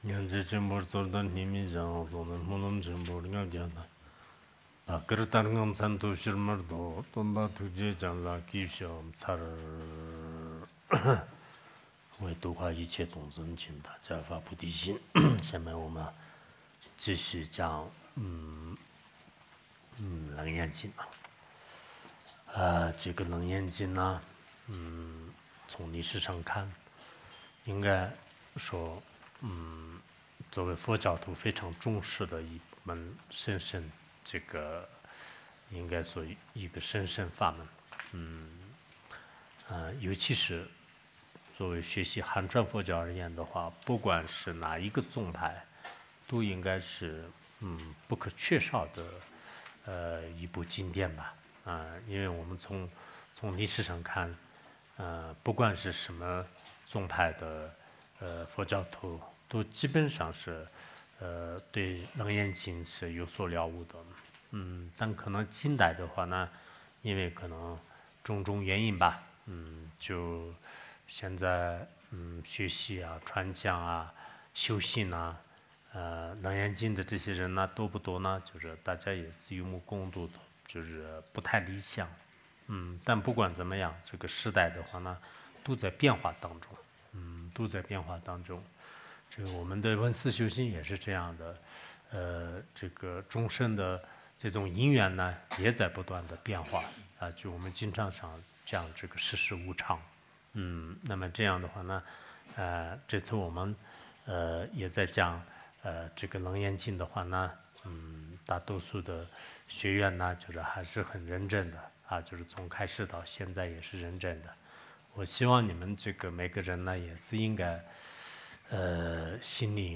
《楞严经》讲解 12 » 智海光明